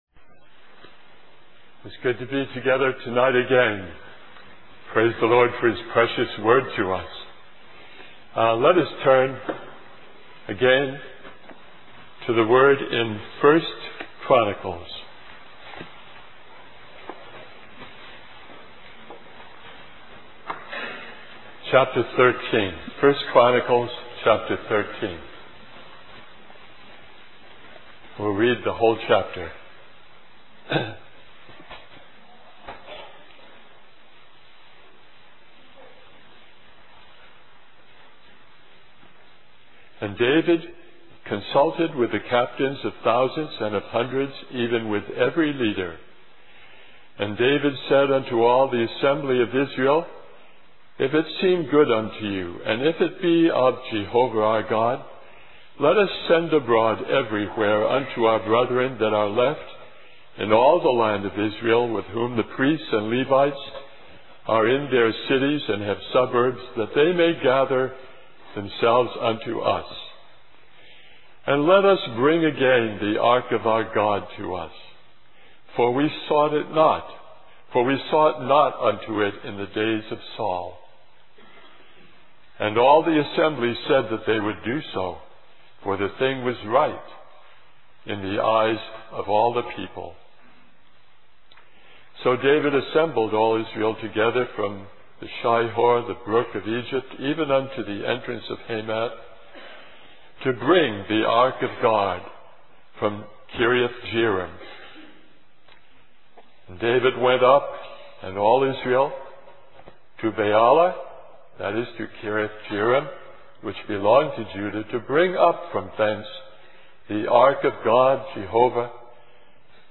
2001 Christian Family Conference Stream or download mp3 Summary The speaker uses the story of David bringing the Ark into Jerusalem to illustrate David love for the House of God, and to illuminate our life in the Church. He states that the Ark represents the full testimony and presence of Christ, and that the cart used by David represented the ways of the world.